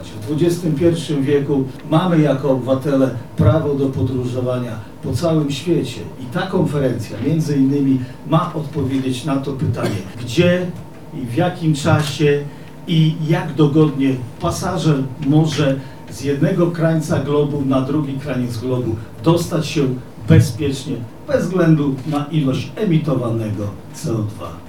Jarosław Stawiarski-mówił Marszałek województwa lubelskiego, Jarosław Stawiarski